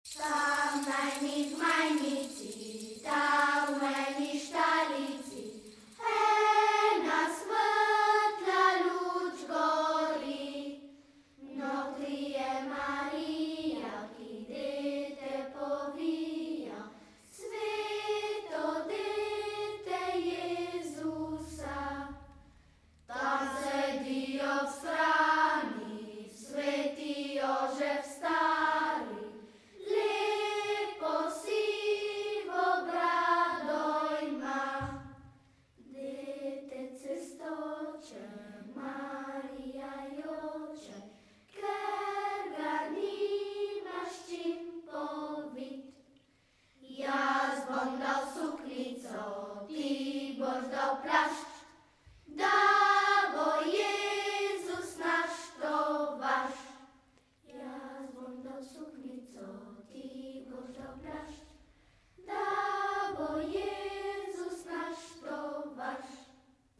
BOŽIČNE IN NOVOLETNE KOLEDNICE S TRŽAŠKEGA